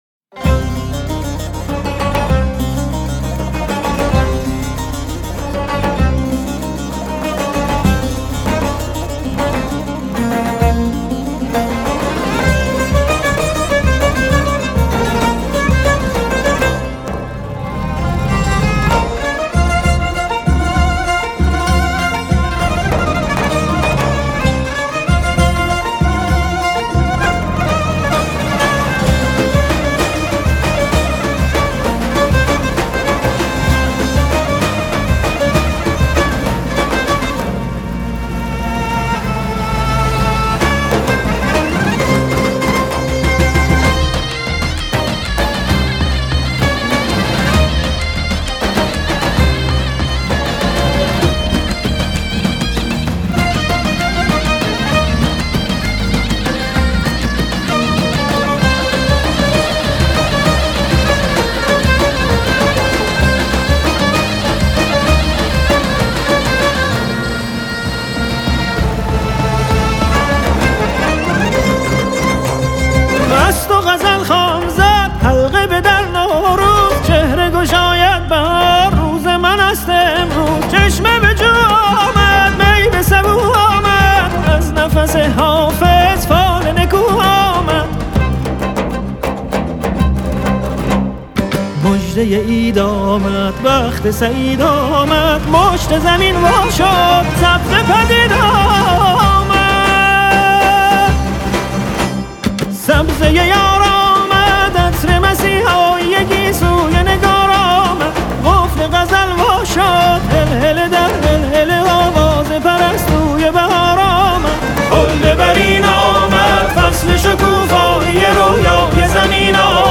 Mit seiner charakteristischen gefühlvollen Stimme